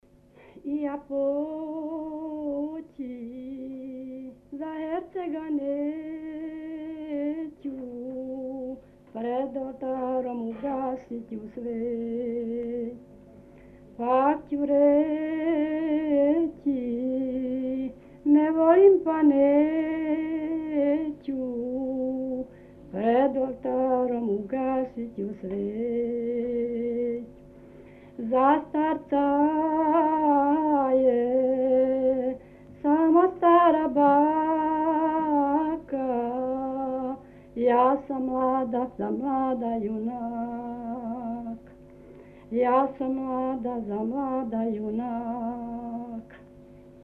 Тема: Лирске песме
Место: Чанад